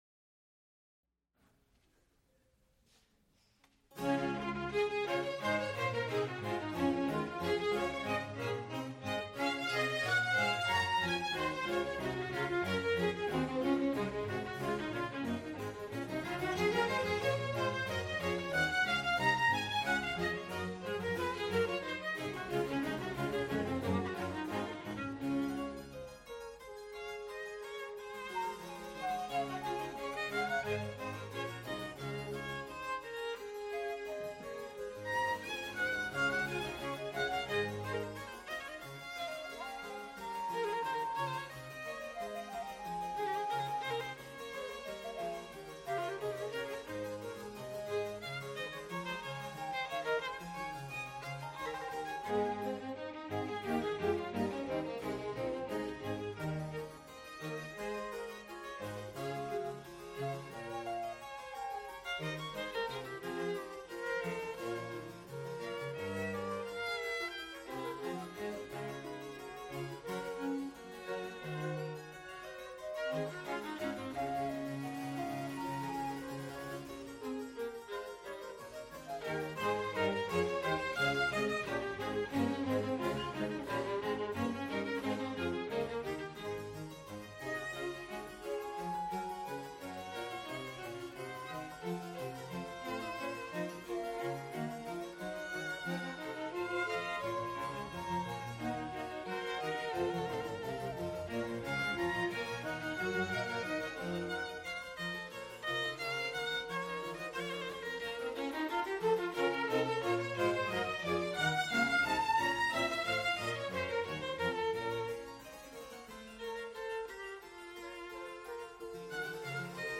Recorded live February 28, 1978, Frick Fine Arts Auditorium, University of Pittsburgh.
musical performances